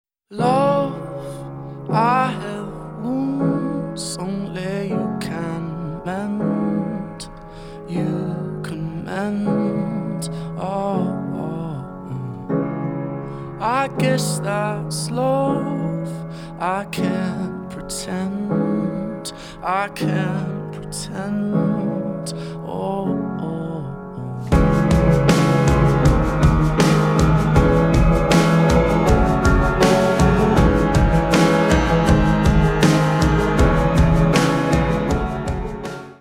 • Качество: 320, Stereo
мужской вокал
красивые
спокойные
легкий рок
фортепиано
авторская песня